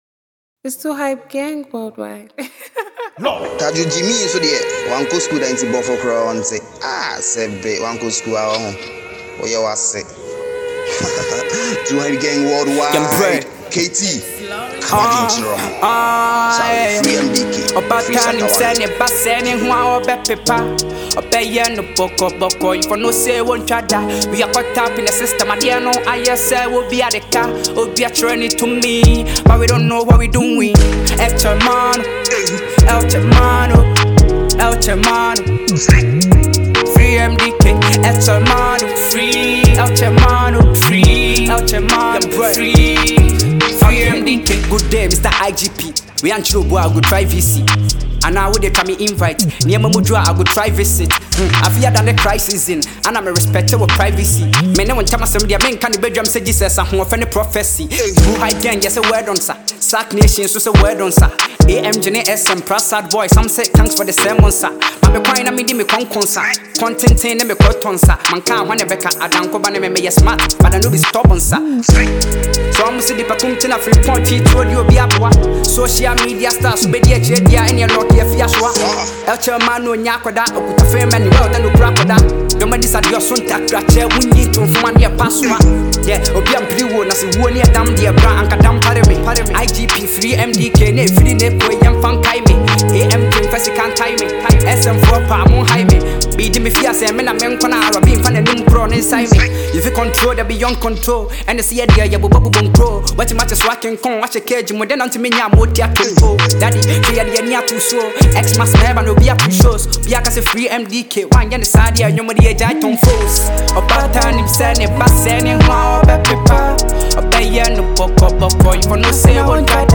Hip-pop song